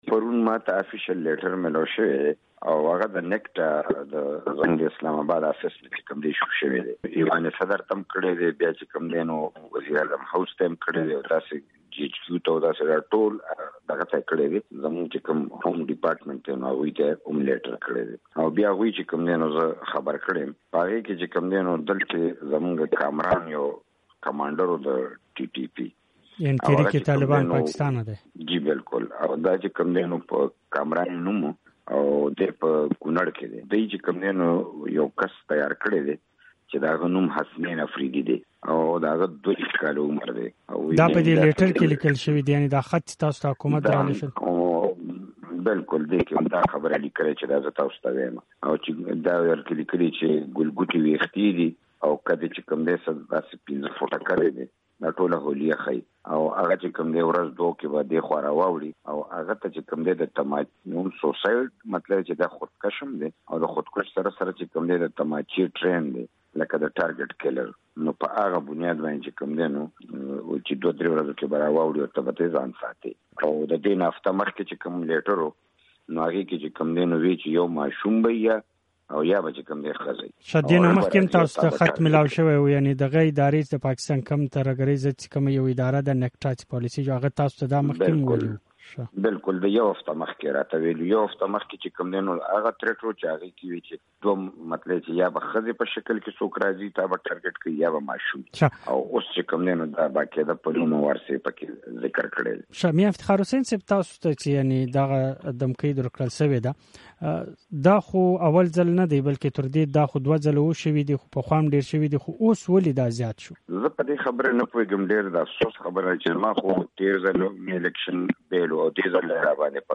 له میا افتخار سره مرکه دلته واورئ